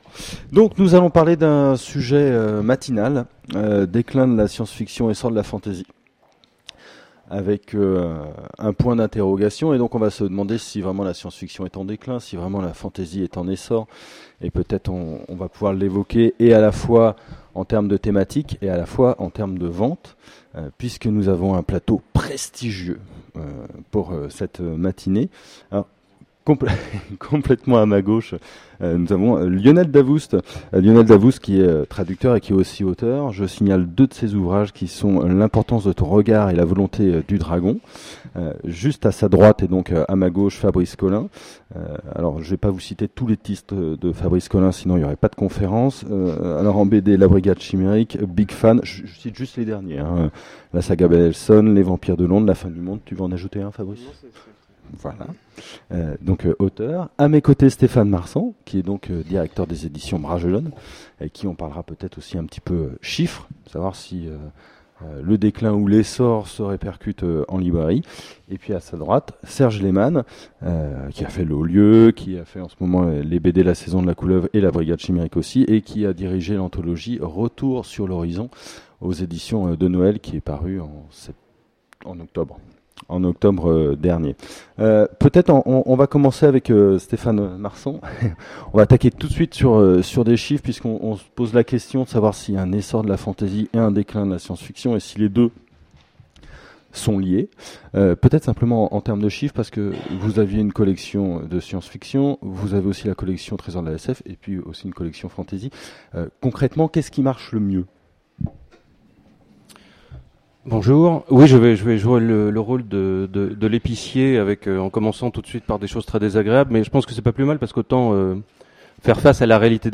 Voici l'enregistrement de la conférence Déclin de la science fiction, essor de la fantasy ? aux Imaginales 2010